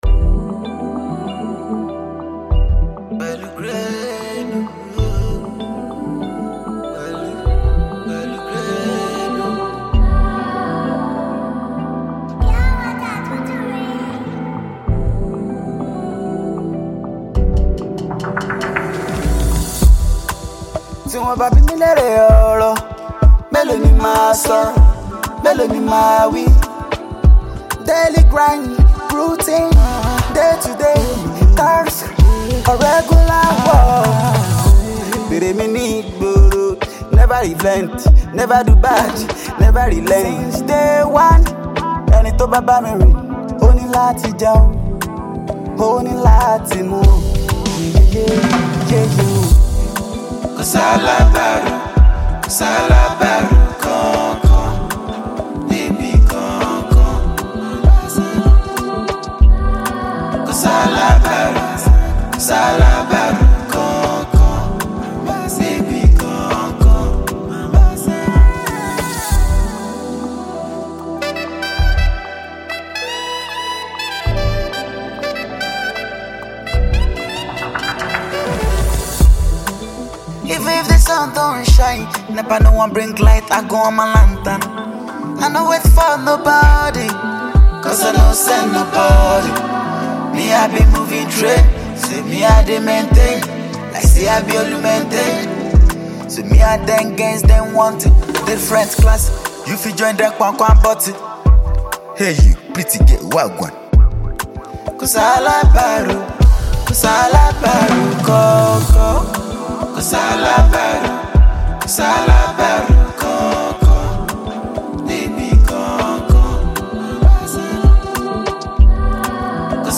• Genre: Nigerian Alté / Afro-Beat
soulful vocals convey a deep sense of vulnerability